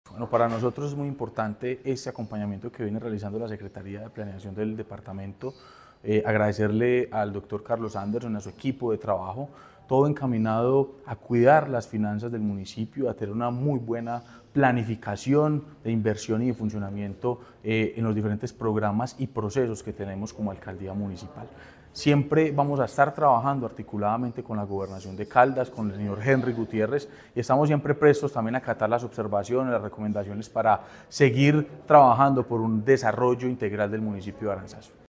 Sebastián Merchán, alcalde de Aranzazu.